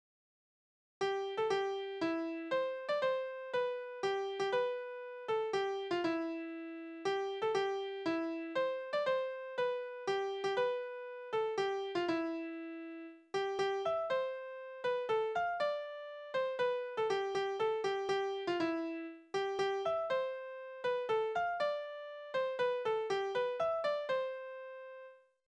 Tonart: C-Dur
Taktart: 3/4
Tonumfang: kleine Dezime
Besetzung: vokal